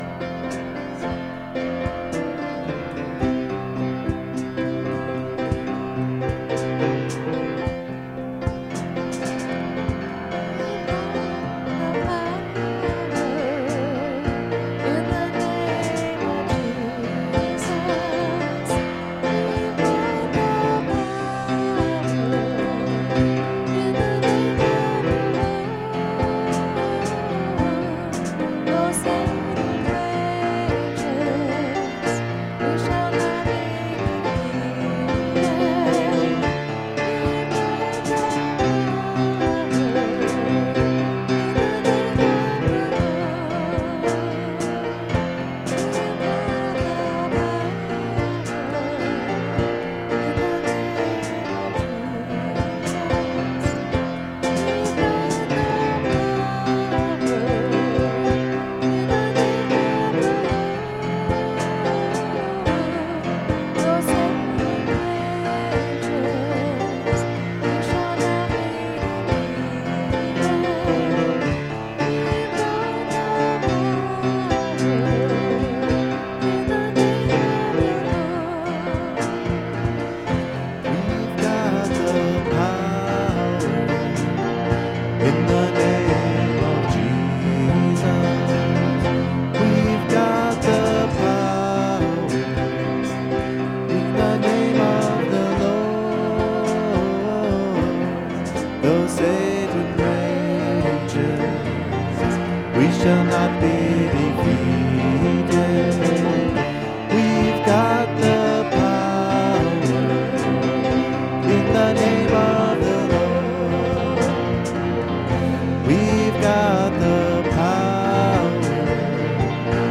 Sunday Night Service
Altar Call Music